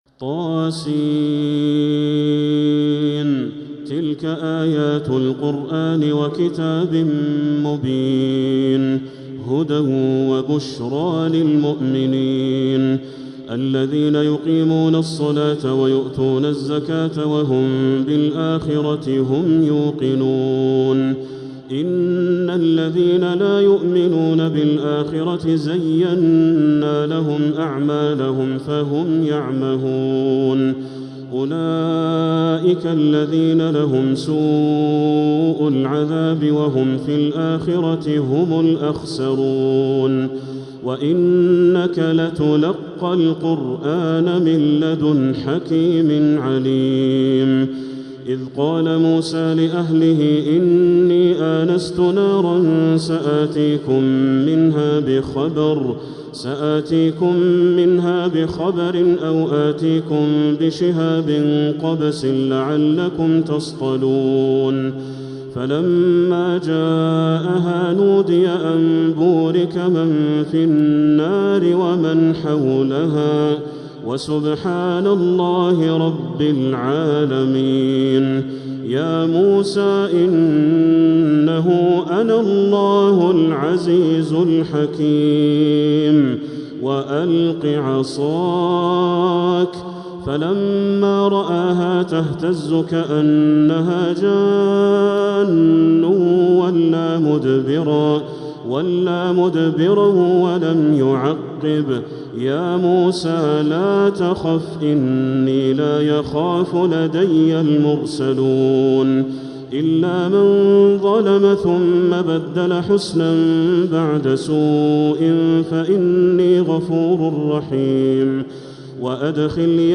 تلاوة مسترسلة
من الحرم المكي | Surat An-Namal